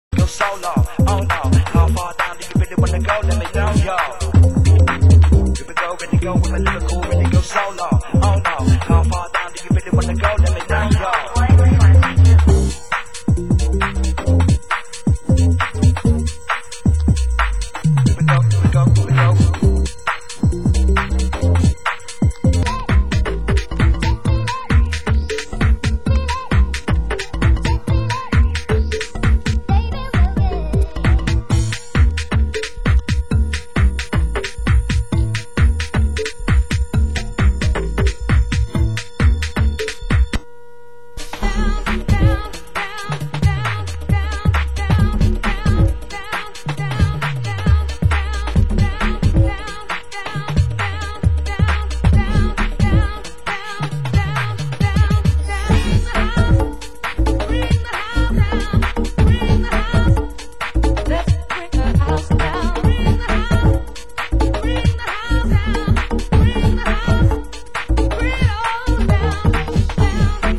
Genre: UK Garage